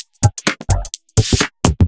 Index of /VEE/VEE2 Loops 128BPM
VEE2 Electro Loop 296.wav